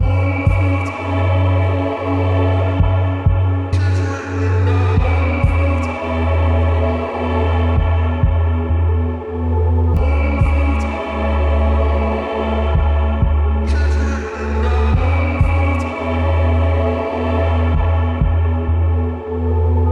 BPM 96